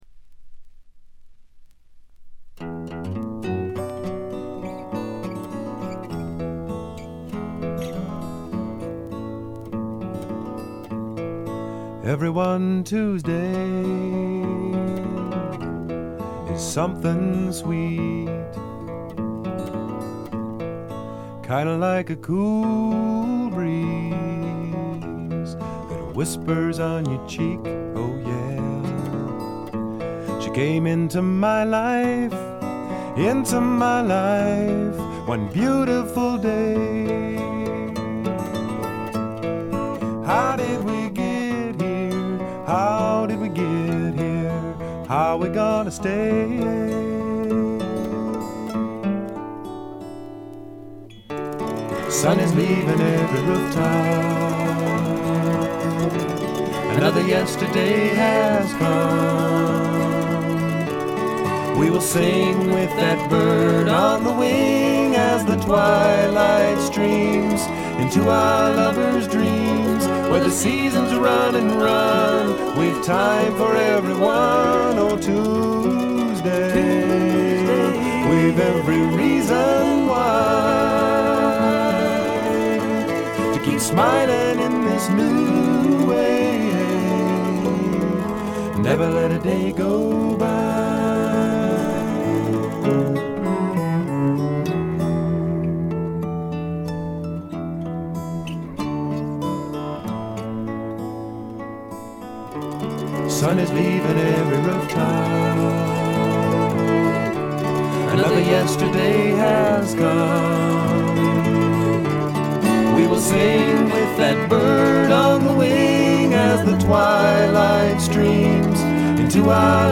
微細なチリプチわずか。
全体に静謐で、ジャケットのようにほの暗いモノクロームな世界。
試聴曲は現品からの取り込み音源です。